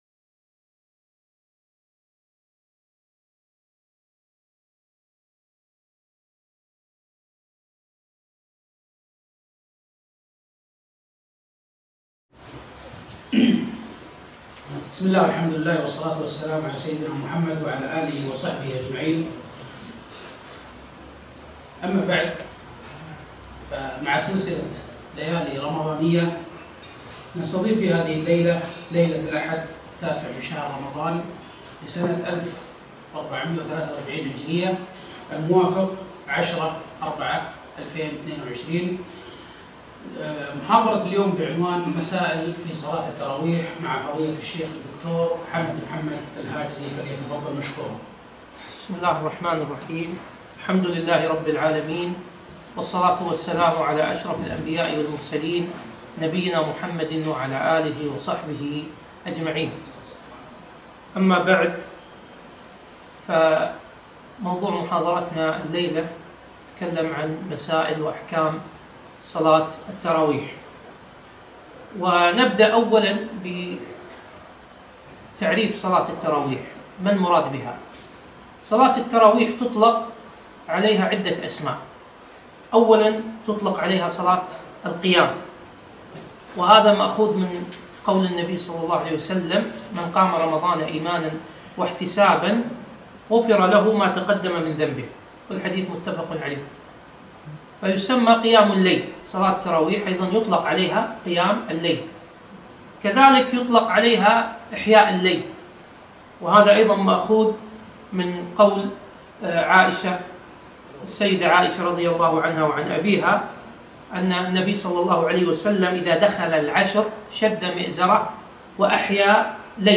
محاضرة - مسائل في صلاة التراويح